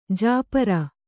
♪ jāpara